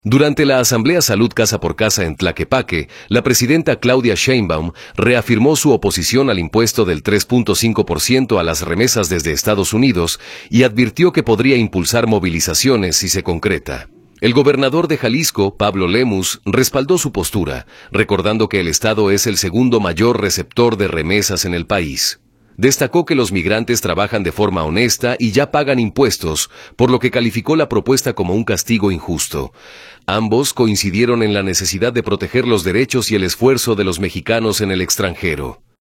Durante la Asamblea Salud Casa por Casa en Tlaquepaque, la presidenta Claudia Sheinbaum reafirmó su oposición al impuesto del 3.5 por ciento a las remesas desde Estados Unidos y advirtió que podría impulsar movilizaciones si se concreta. El gobernador de Jalisco, Pablo Lemus, respaldó su postura, recordando que el estado es el segundo mayor receptor de remesas en el país.